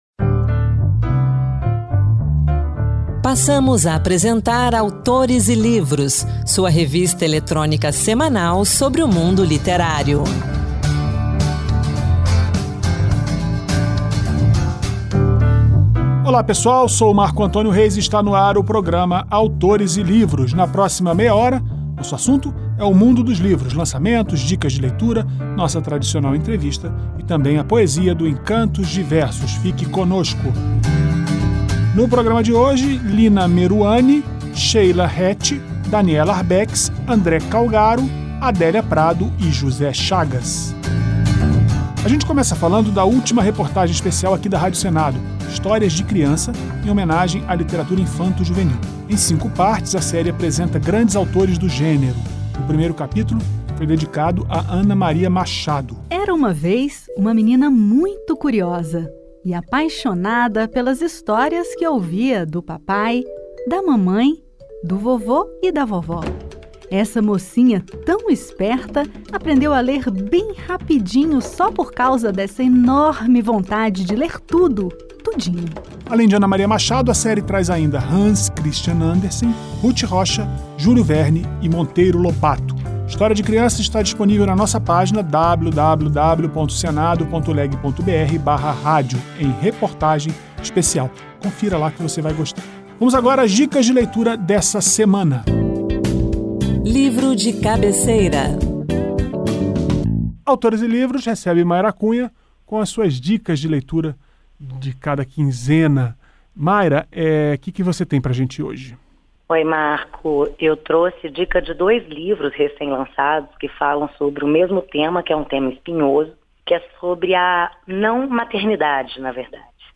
Com o tema Palavra, o “Encanto de Versos” traz poesias Adélia Prado e José Chagas.